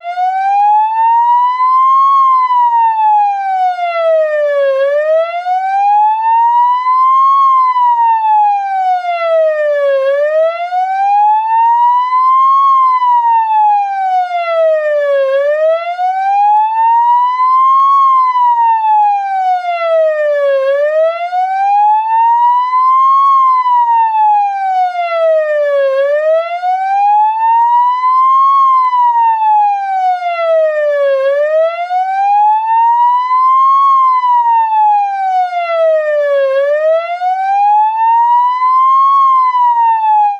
Sirena de ambulancia continua